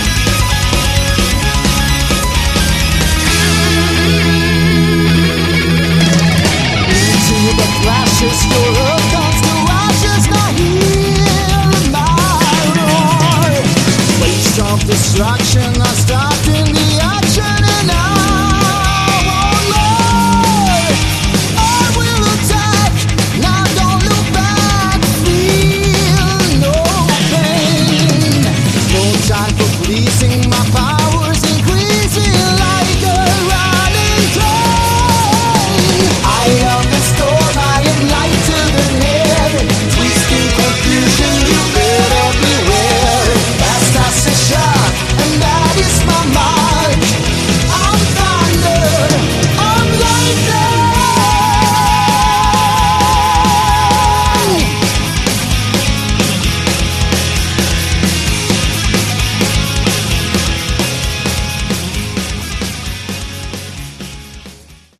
Category: Hard Rock
vocals
guitars
bass
drums
keyboards